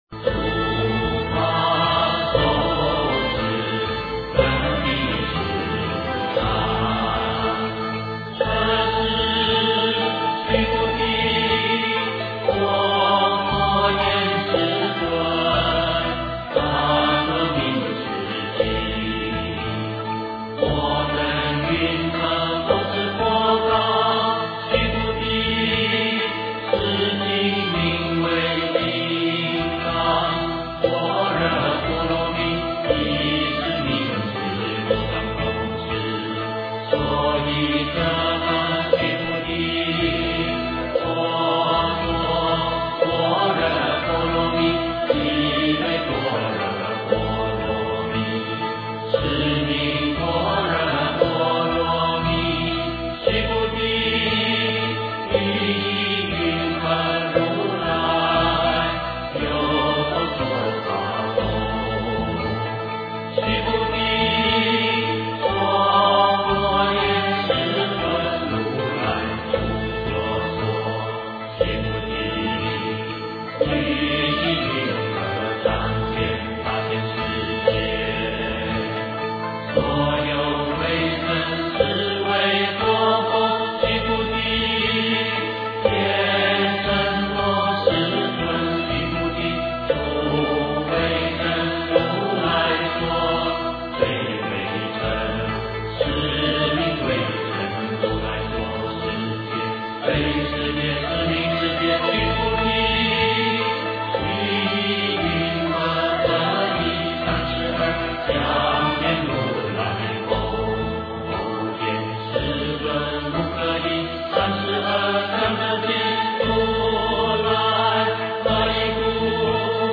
金刚经-如法受持分第十三 诵经 金刚经-如法受持分第十三--未知 点我： 标签: 佛音 诵经 佛教音乐 返回列表 上一篇： 金刚经-无为福胜分第十一 下一篇： 金刚经-持经功德分第十五 相关文章 六字大明咒--齐豫 六字大明咒--齐豫...